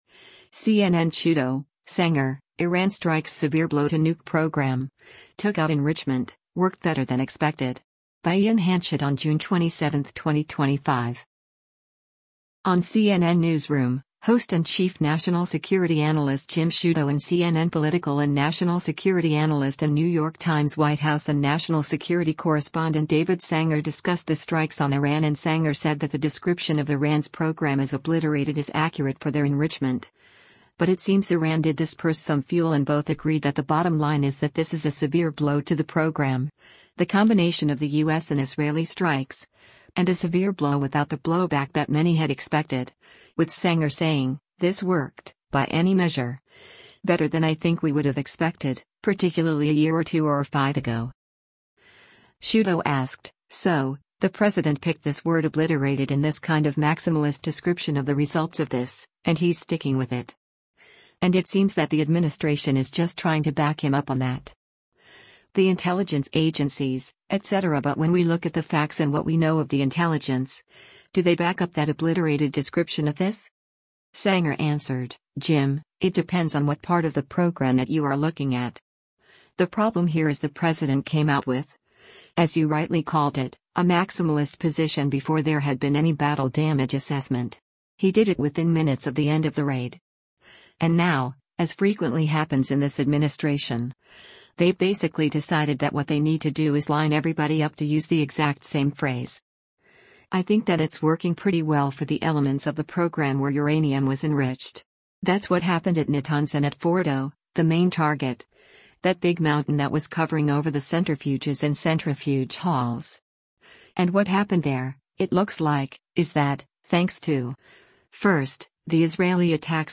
On “CNN Newsroom,” host and Chief National Security Analyst Jim Sciutto and CNN Political and National Security Analyst and New York Times White House and National Security Correspondent David Sanger discussed the strikes on Iran and Sanger said that the description of Iran’s program as obliterated is accurate for their enrichment, but it seems Iran did disperse some fuel and both agreed that the bottom line is that “this is a severe blow to the program, the combination of the U.S. and Israeli strikes, and a severe blow without the blowback that many had expected,” with Sanger saying, “this worked, by any measure…better than I think we would have expected, particularly a year or two or five ago.”